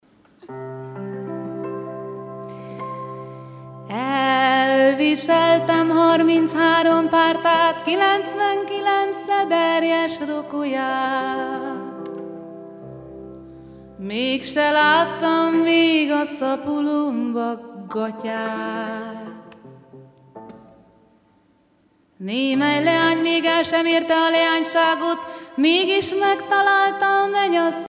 Concert a Brugges Festival, Belgique
Pièce musicale inédite